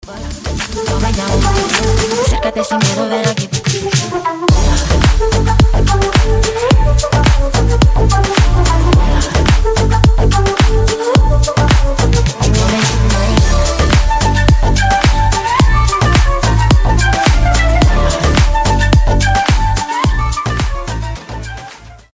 женский вокал
deep house
заводные
dance
красивая мелодия
скрипка
духовые